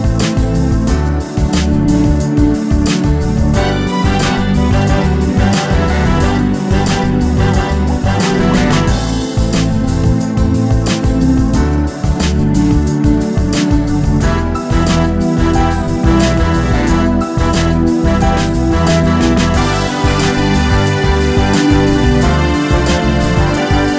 Two Semitones Down Pop (1990s) 3:41 Buy £1.50